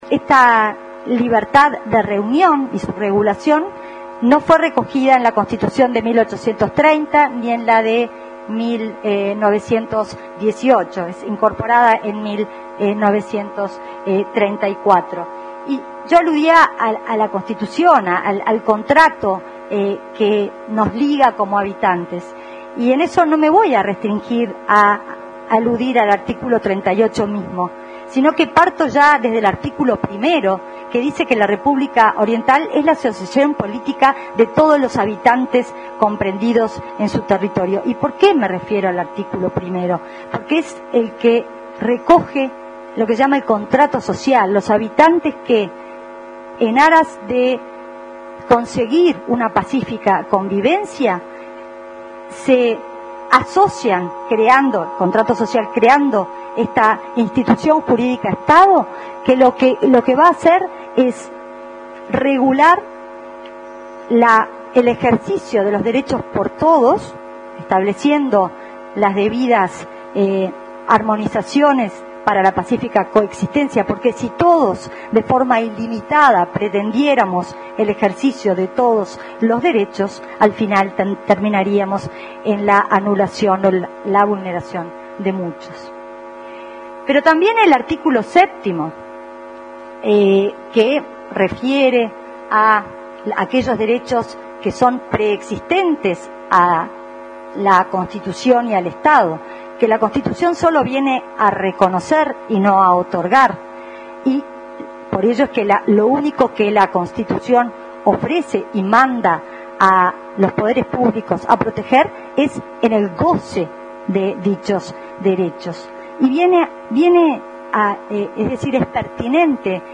La senadora nacionalista, Carmen Asiaín, al momento de exponer , dijo que no se limitaría a aludir al artículo 38, también hizo referencia al artículo 1º de la Constitución, que indica » La República Oriental del Uruguay es la asociación política de todos los habitantes comprendidos dentro de su territorio».